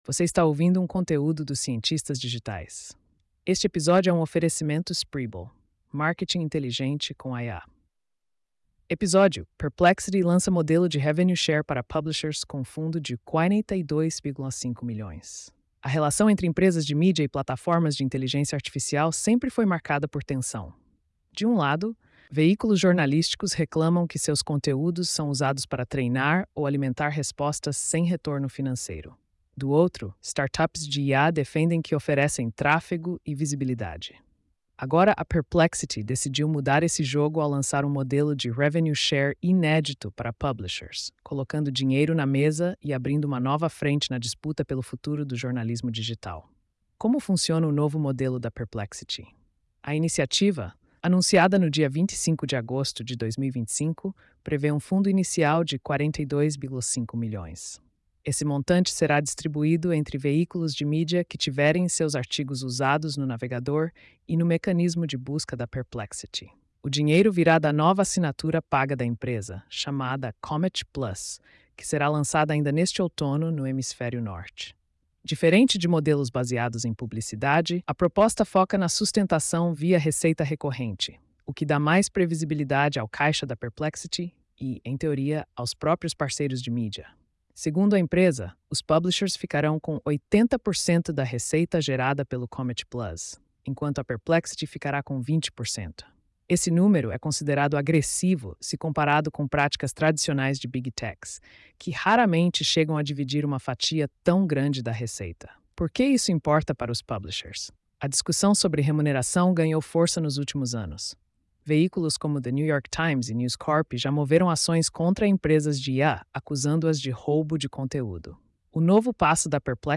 post-4314-tts.mp3